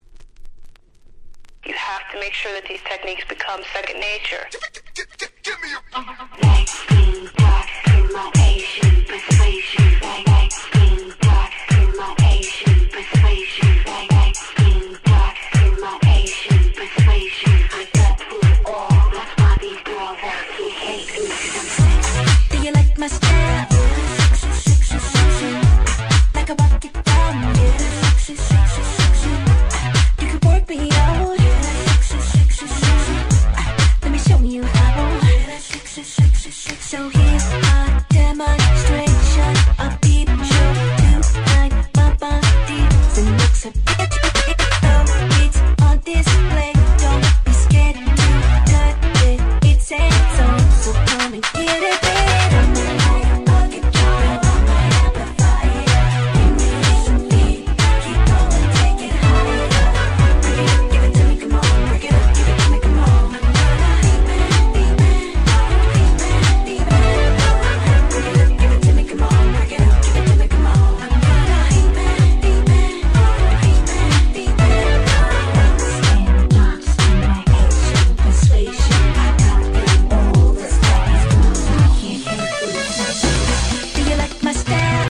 08' Smash Hit R&B !!
どのRemixもフロア映えする即戦力なRemixです！